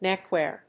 néck・wèar
• / ˈnɛk.wɛɚ(米国英語)
neckwear.mp3